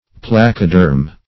Placoderm \Plac"o*derm\, n. [Gr. pla`x, plako`s, tablet + de`rma